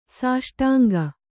♪ sāṣṭānga